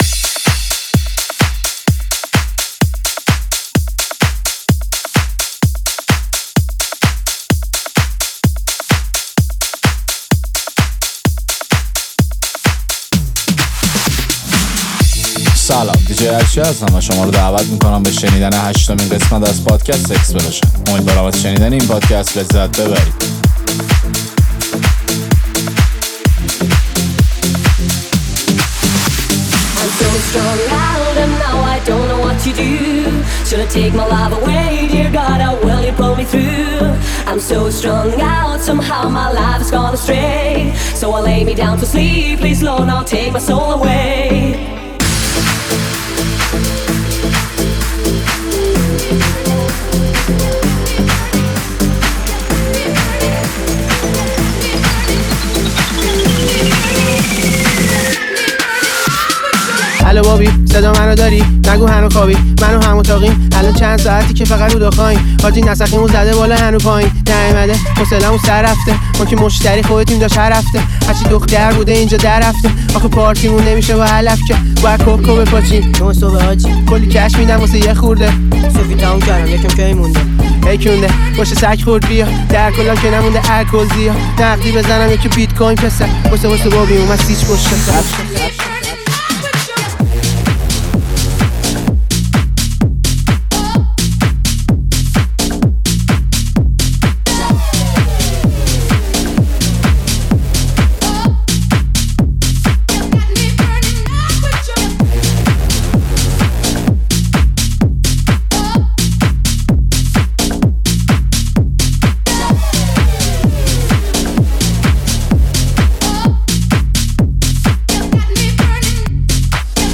persian remix
ریمیکس ایرانی